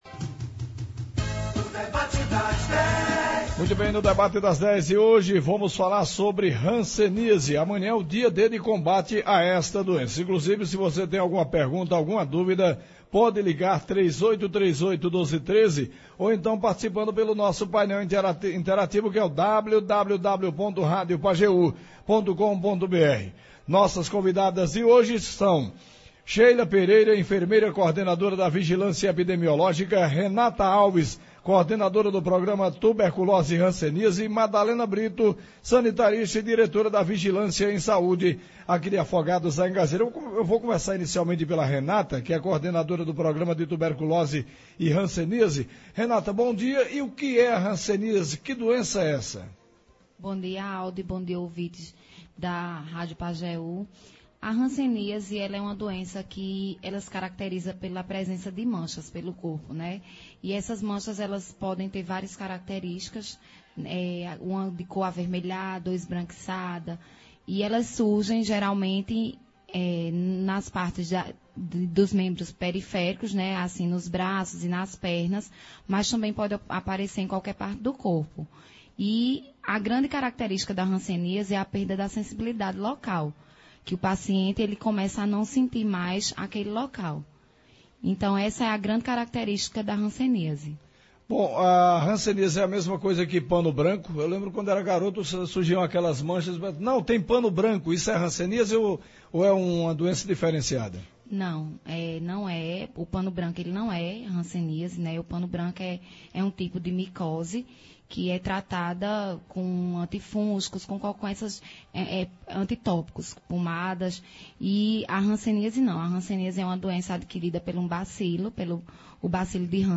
Para falar sobre a hanseníase, prevenção, diagnóstico e tratamento, nos estúdios da Pajeú